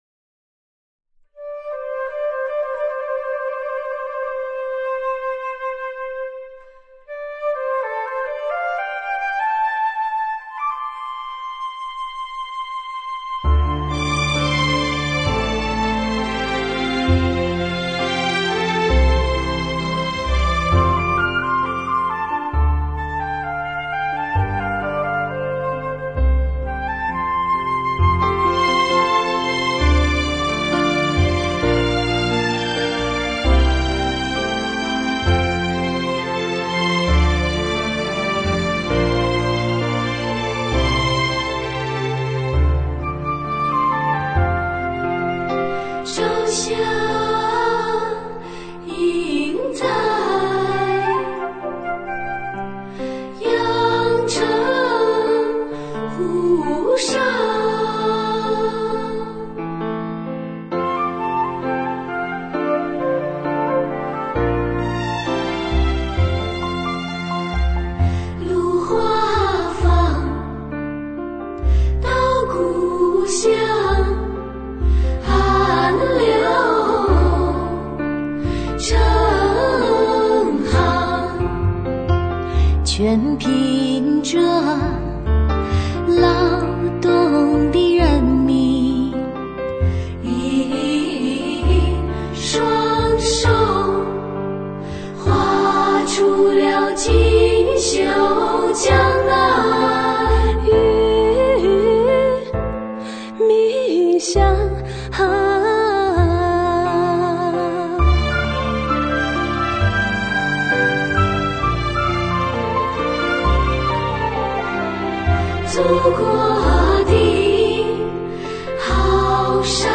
经典名段，流行演唱，国内首创，石破天惊！